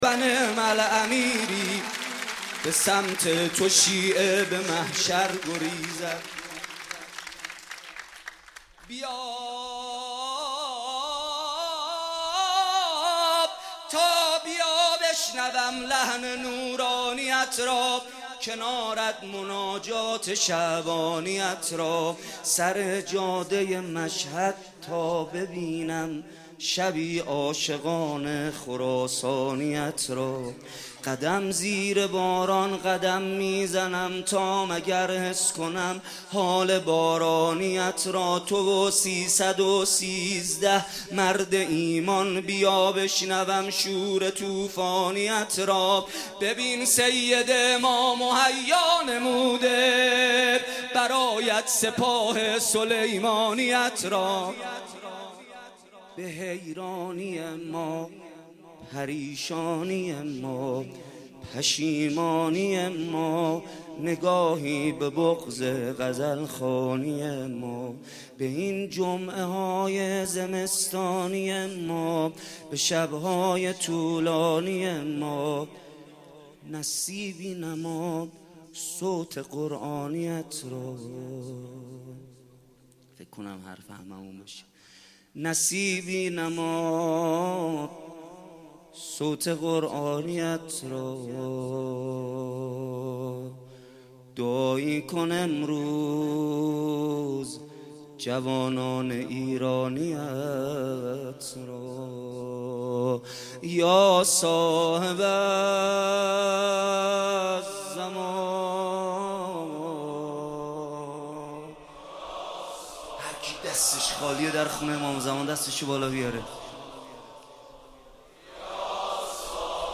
جشن نیمه شعبان/هیت الرضا(ع)